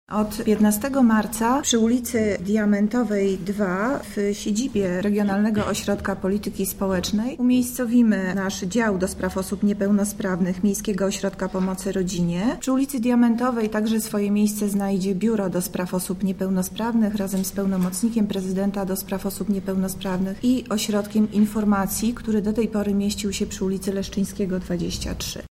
-Nowa lokalizacja nie będzie posiadać barier architektonicznych utrudniających dostęp oraz dociera do niej wiele linii komunikacji miejskiej – mówi zastępca prezydenta Lublina, Monika Lipińska: